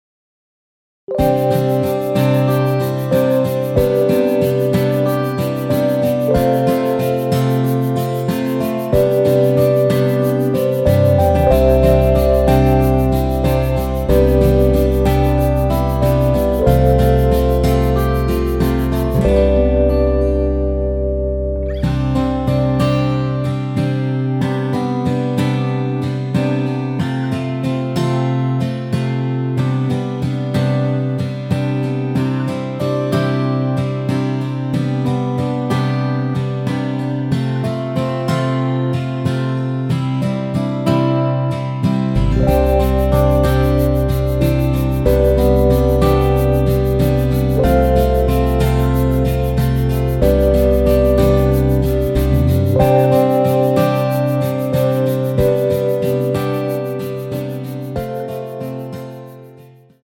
원키에서(-1)내린 MR입니다.
◈ 곡명 옆 (-1)은 반음 내림, (+1)은 반음 올림 입니다.
앞부분30초, 뒷부분30초씩 편집해서 올려 드리고 있습니다.